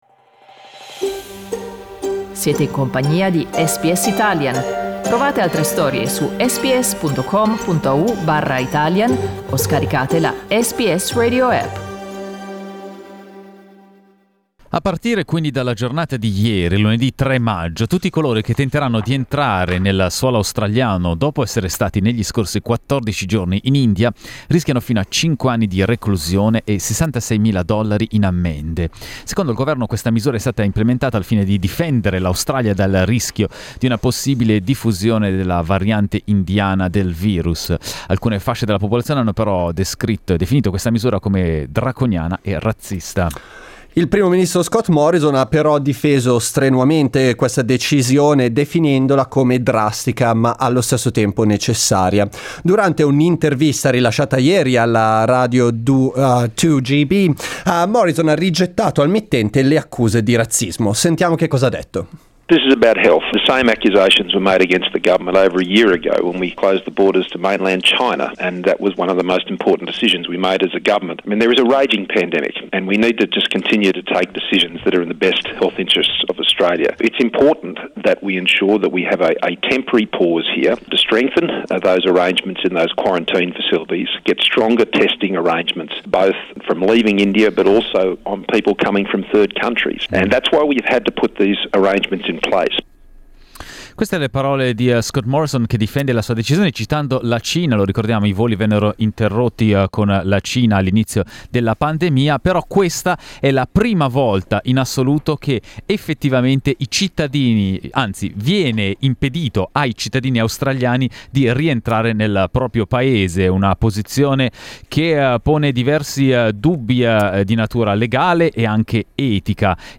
Noi abbiamo chiesto ai nostri ascoltatori e alle nostre ascoltatrici che cosa pensano di questa decisione.